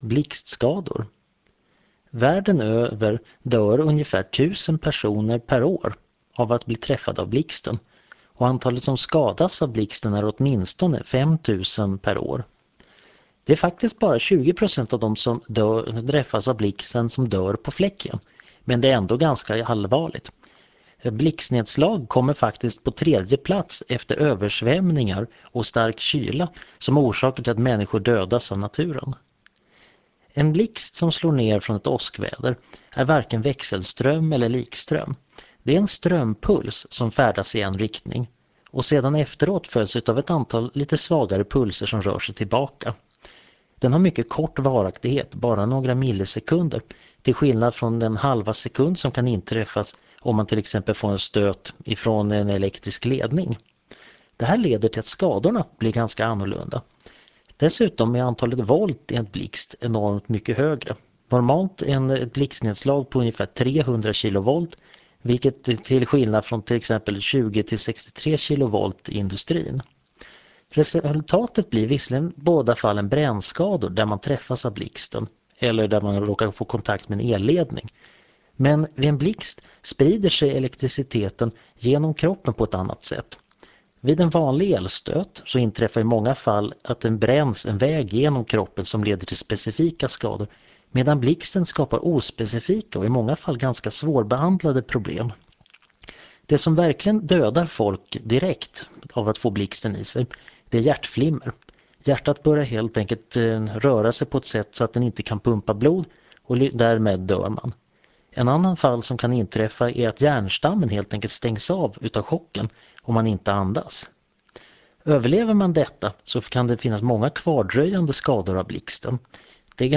Föredraget handlar om Fysik